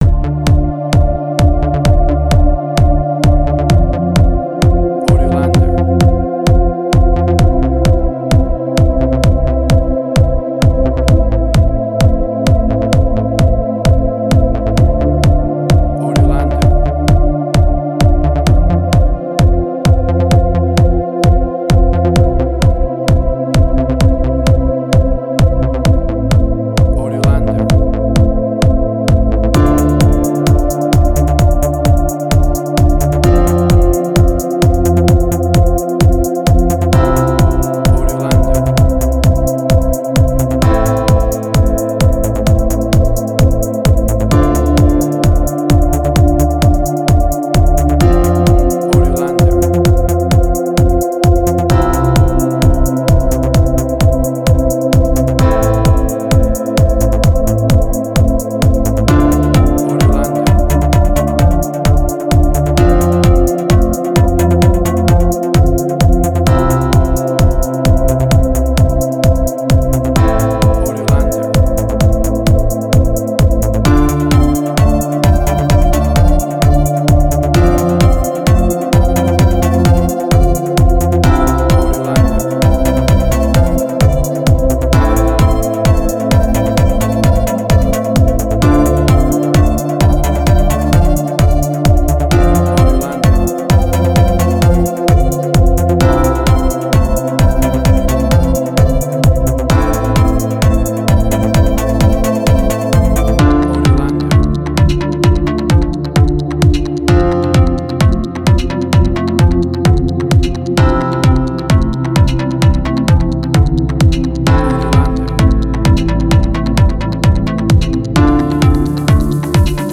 House.
Tempo (BPM): 130